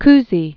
(kzē), Robert Joseph Known as "Bob."